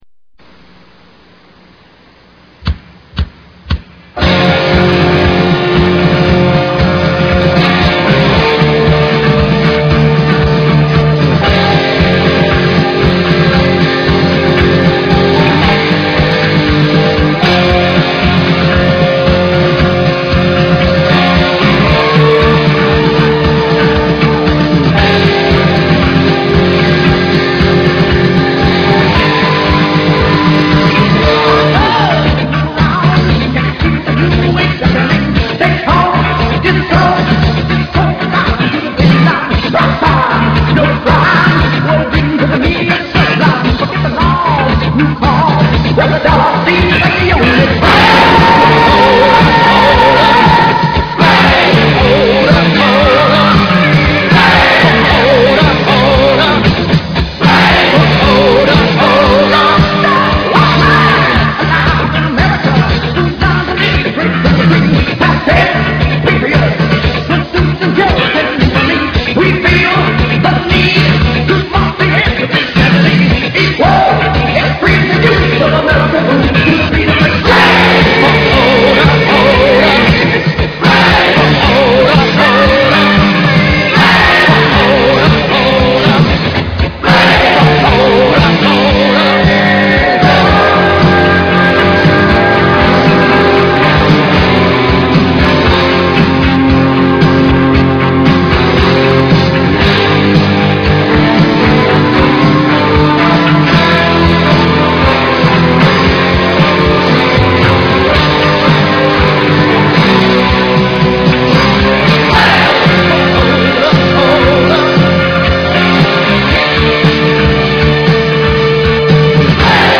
Unveröffentlichter Demo Song